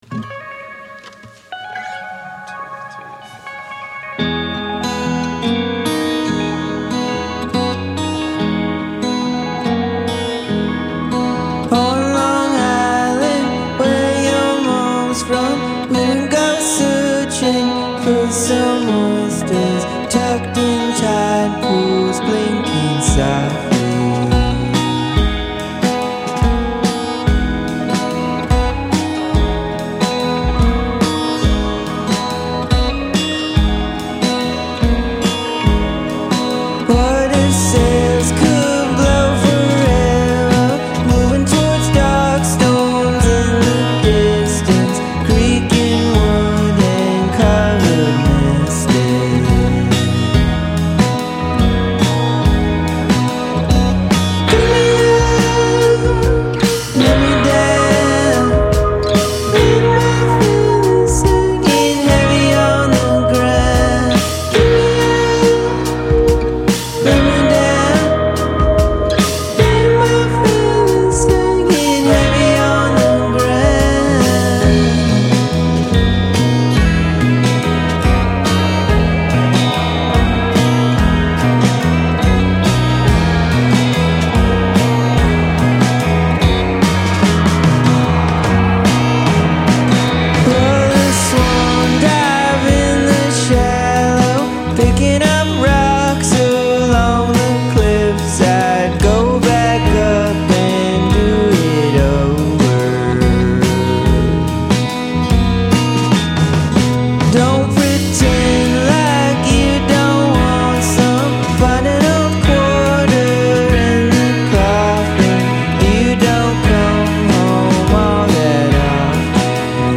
The beautifully sublime song
indie-pop band